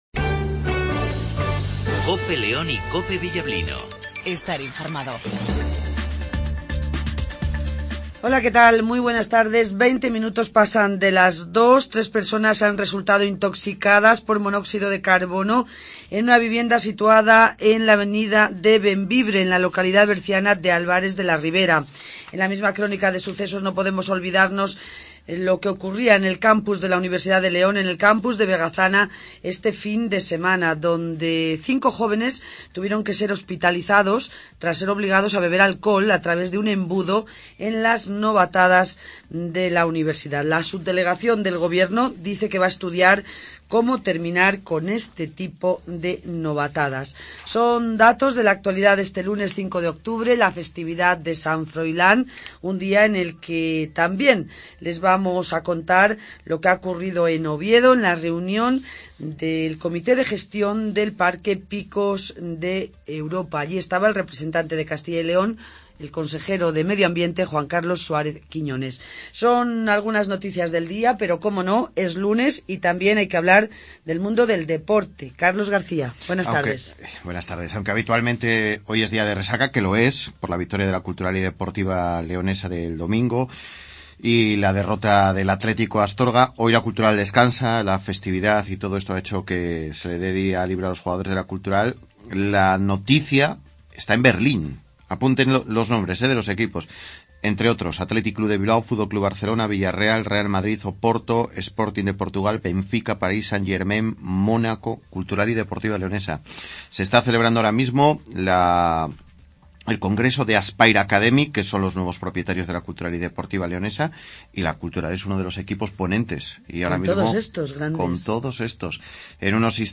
INFORMATIVO MEDIODIA
juan carlos suárez quiñones ( delegado del gobierno en cyl y consejero de medio ambiente )